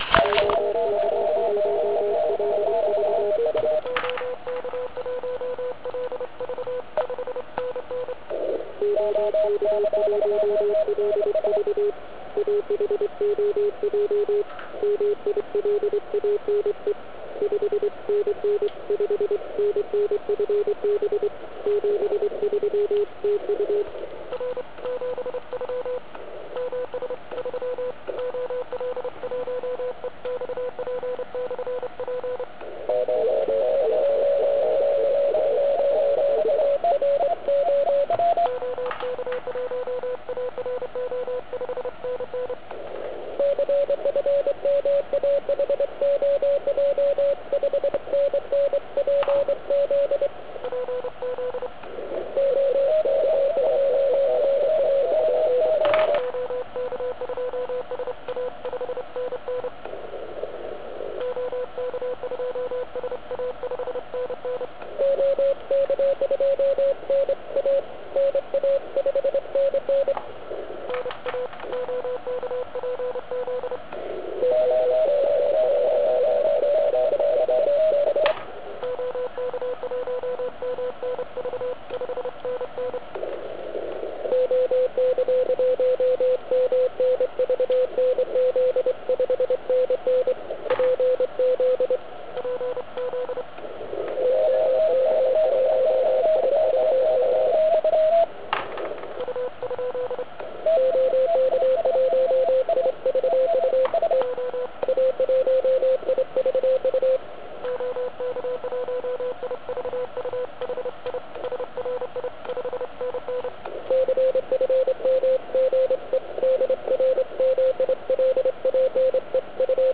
Tak vidíte přátelé, PileHup se dá vyvolat i v domácích podmínkách.